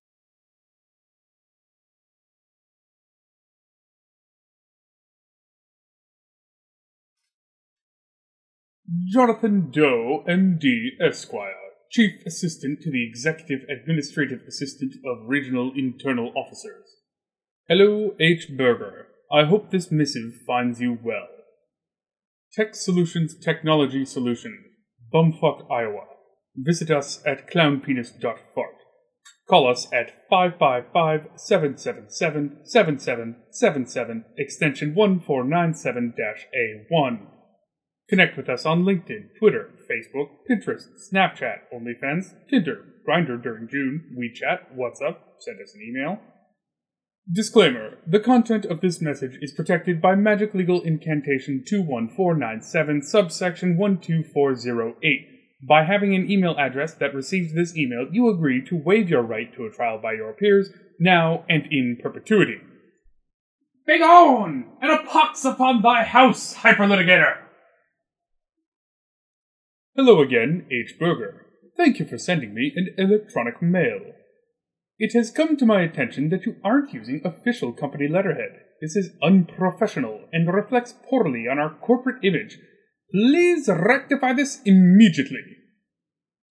removed bg noises for pretentious email vo
pretentious email simulation.ogg